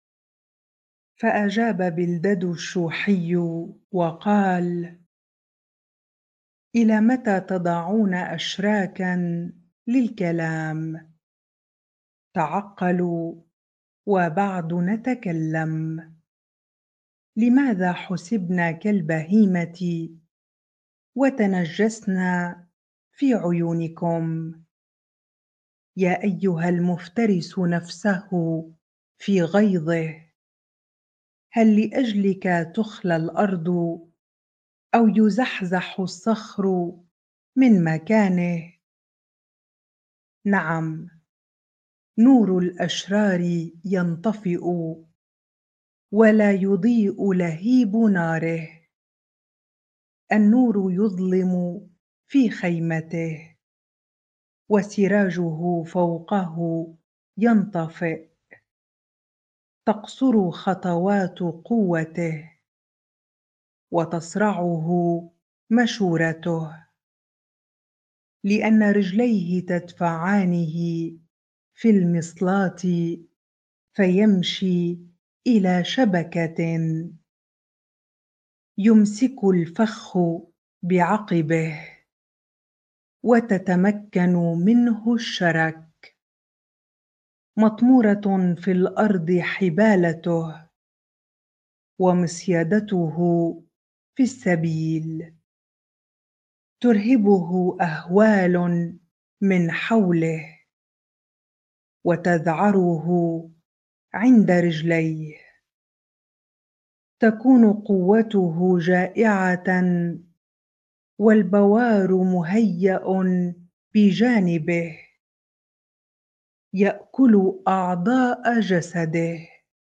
bible-reading-Job 18 ar